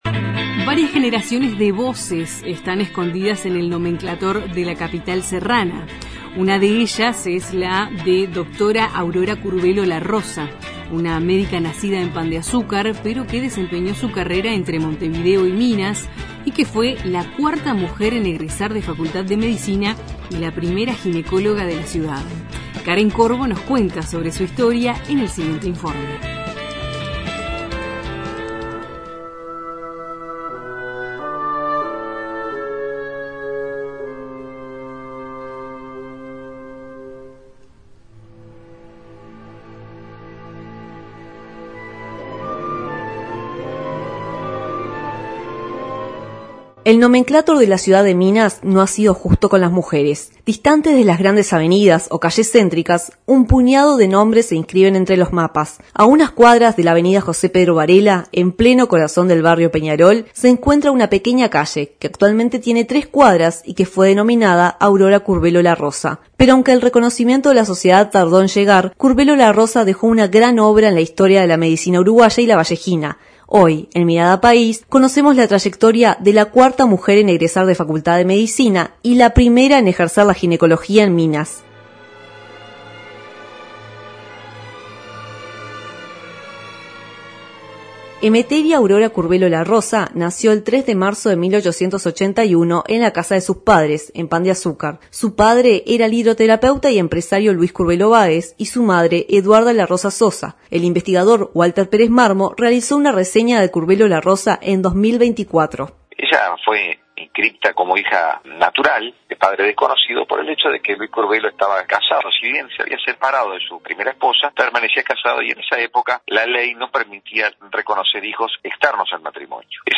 Los informes de nuestros corresponsales de Cerro Largo, Colonia Oeste y Lavalleja.